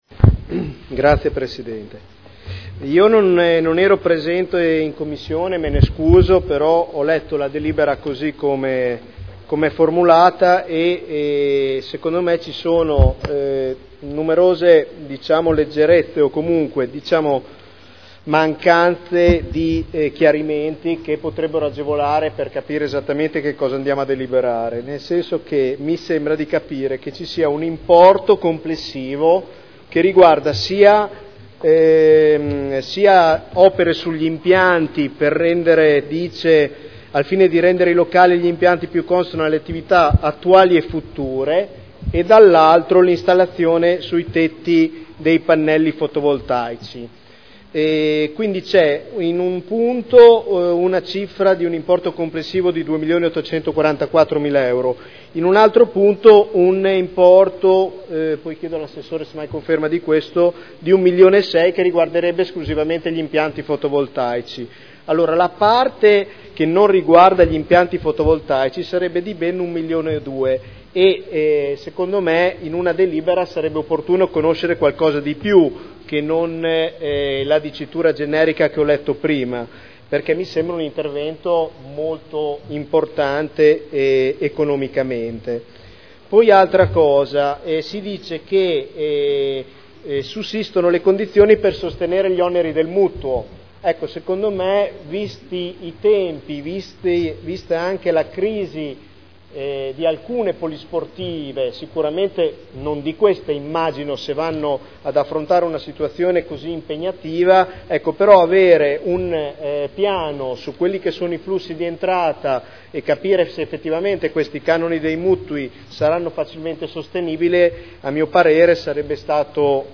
Nicola Rossi — Sito Audio Consiglio Comunale
Seduta del 21/03/2011. Dibattito su proposta di deliberazione: Diritto di superficie a favore della Cooperativa Spazio Unimmobiliare per gli impianti ubicati presso la Polisportiva Saliceta San Giuliano e presso la Polisportiva Gino Nasi – Autorizzazione a iscrivere ipoteca”